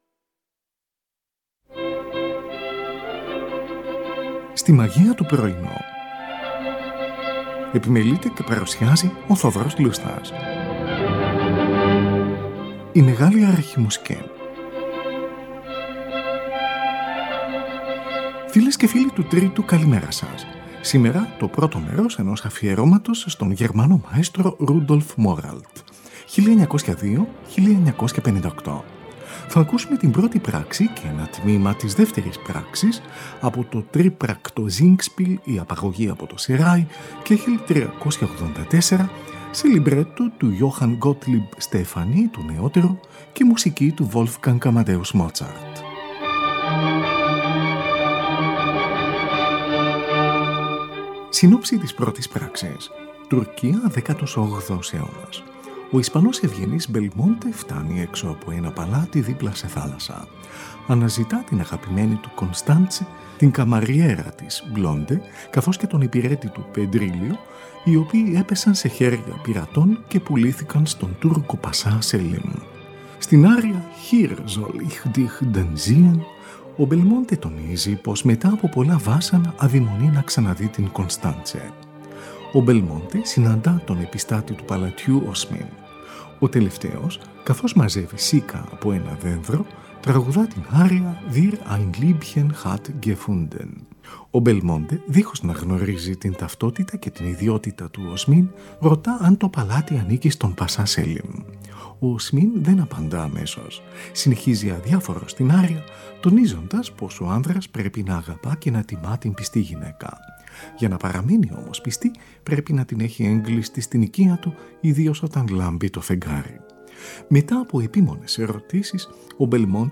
Wolfgang Amadeus Mozart: Η απαγωγή από το Σεράι, Κ.384, τρίπρακτο singspiel, σε λιμπρέτο του Johann Gottlieb Stephanie, του νεότερου.
Λαμβάνουν μέρος οι καλλιτέχνες: Belmonte, ένας Ισπανός ευγενής, ο τενόρος Anton Dermota. Konstanze, αγαπημένη του Belmonte, η υψίφωνος Elisabeth Schwarzkopf.
Τη Χορωδία της Βιεννέζικης Ραδιοφωνίας και τη Συμφωνική της Αυστριακής Ραδιοφωνίας διευθύνει ο Rudolf Moralt. Ραδιοφωνική ηχογράφηση σε studio – με λίγες περικοπές – το 1945.